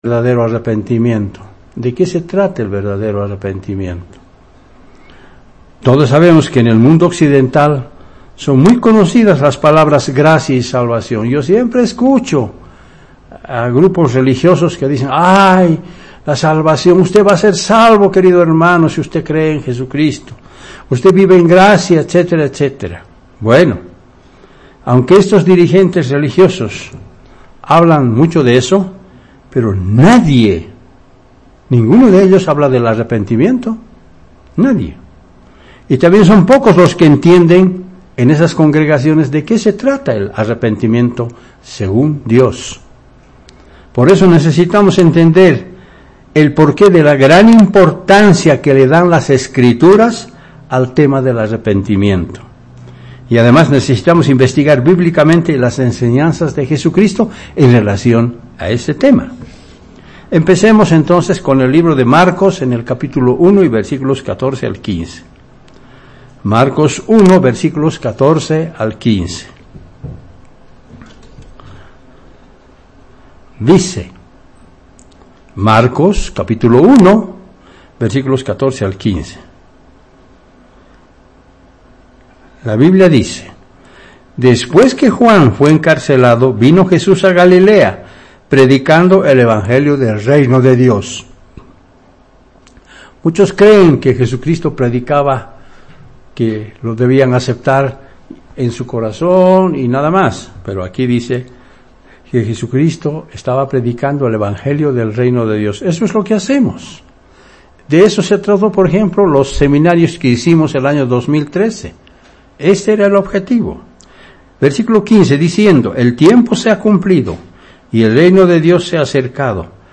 Sermones